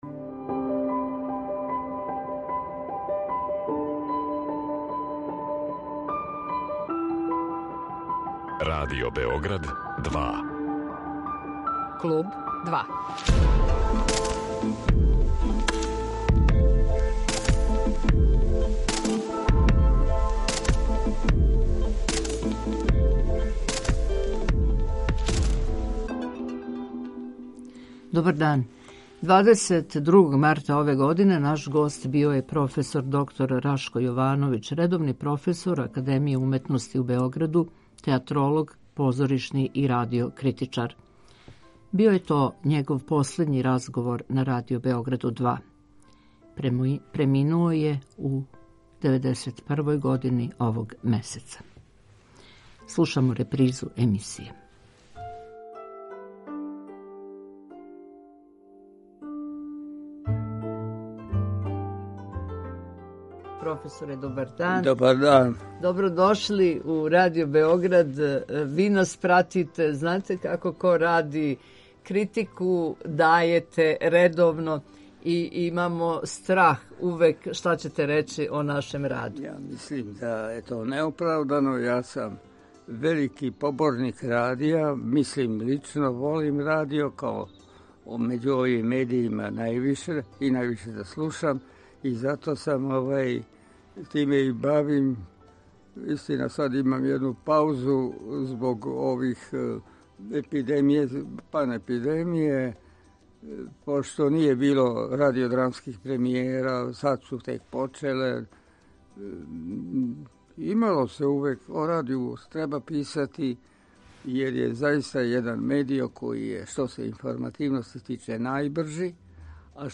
емитујемо разговор